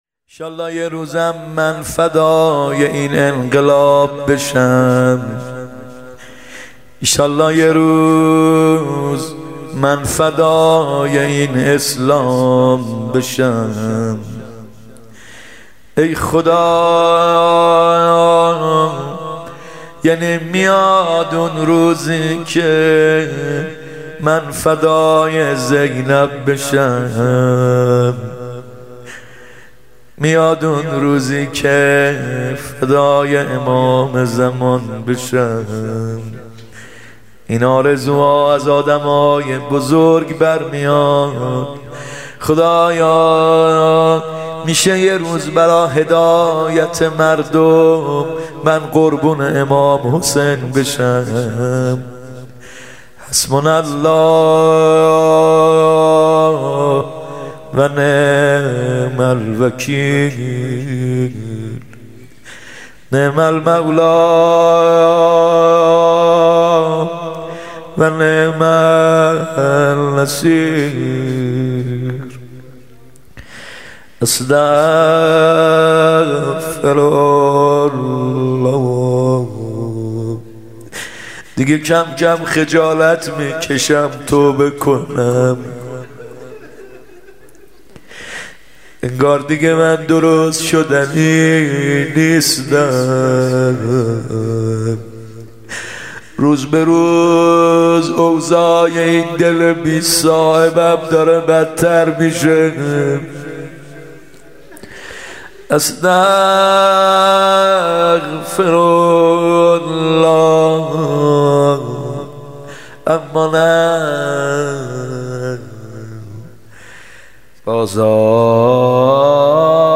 مراسم هفتگی 1395